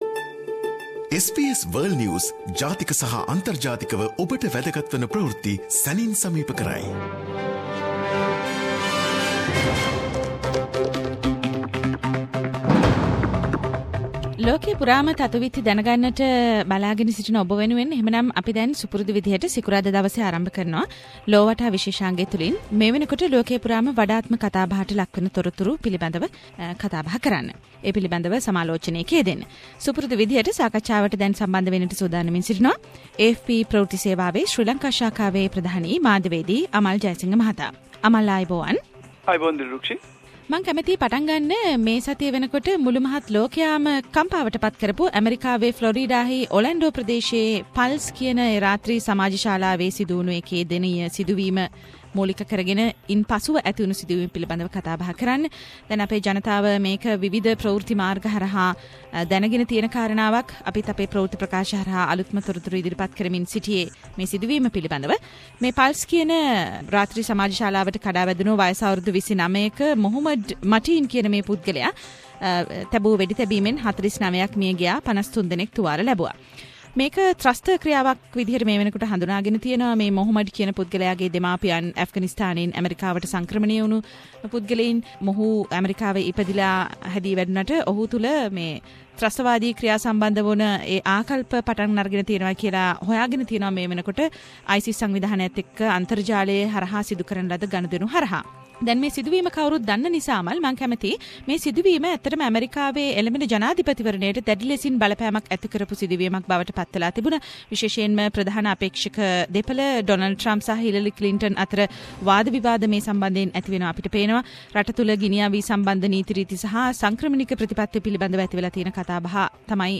SBS Sinhala Around the World - Weekly World News highlights…